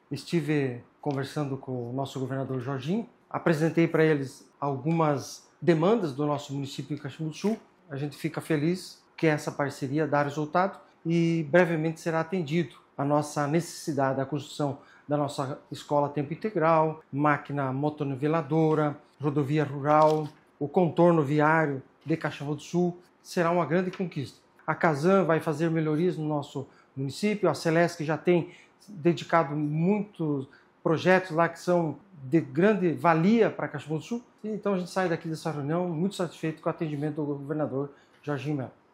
O encontro foi em Chapecó com os prefeitos da Associação de Municípios do Oeste de Santa Catarina (Amosc).
Após a conversa individual com o governador Jorginho Mello, o prefeito de Caxambu do Sul, Edir Marcos Antunes de Mello, fala das demandas atendidas: